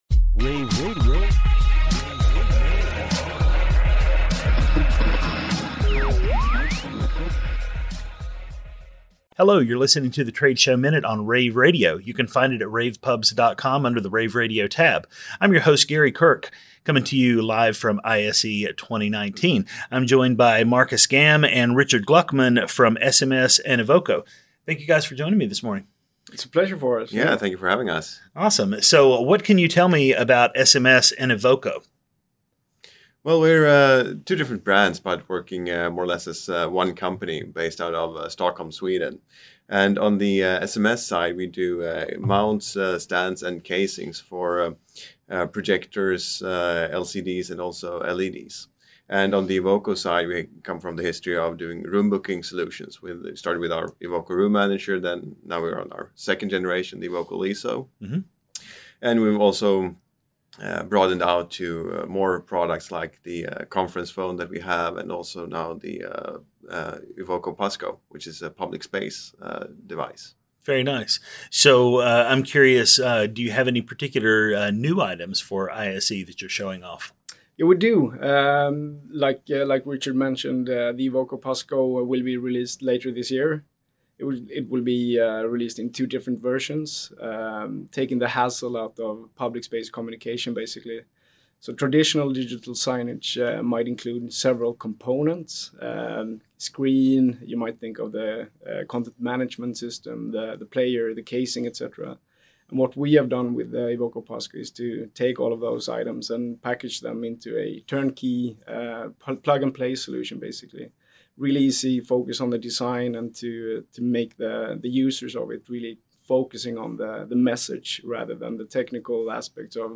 February 7, 2019 - ISE, ISE Radio, Radio, rAVe [PUBS], The Trade Show Minute,